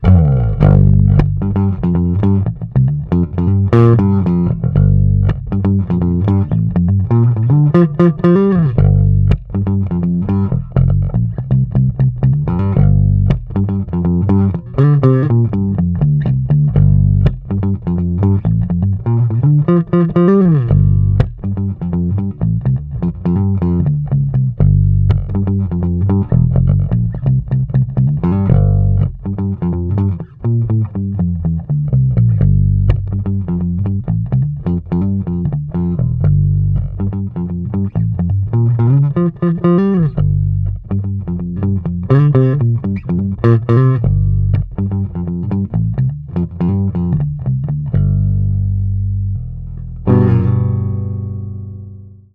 916 Bicentennial Recreation Bass Pickup
Bicentennial-clone-2008-demo.mp3